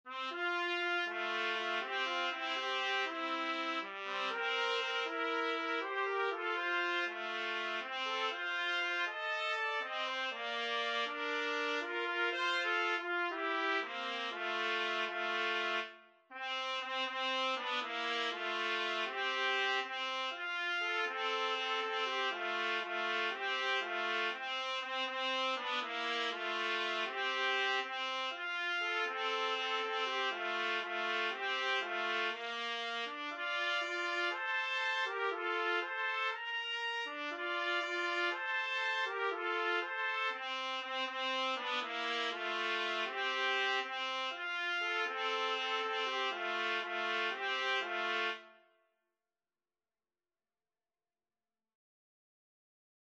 Trumpet 1Trumpet 2
~ = 120 Fast, calypso style